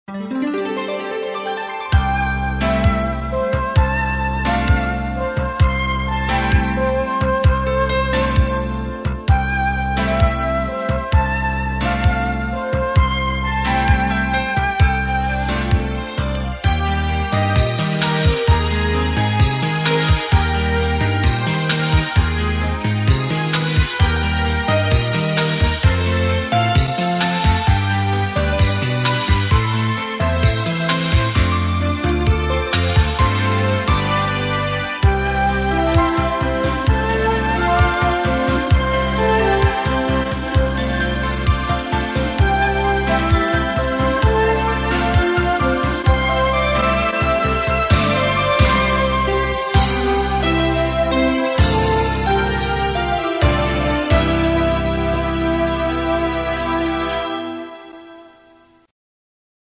Sintonia general de l'emissora